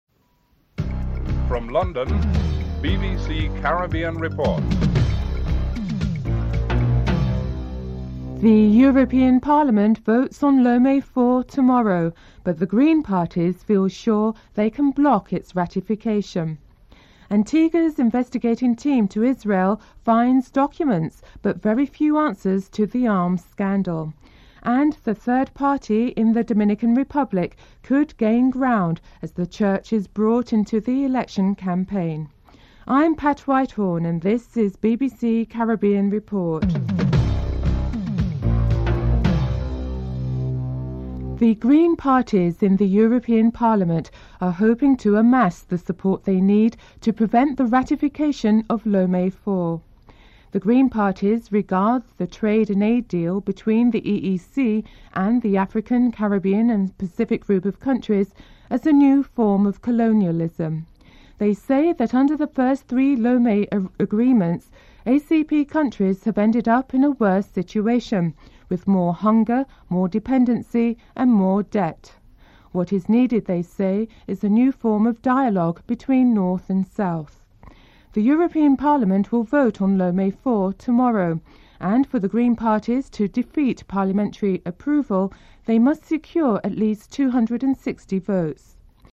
1. Headlines (00:00-00:40)
Interview with Dr. Patrick Lewis, Antigua's representative at the United Nations (05:01-09:00)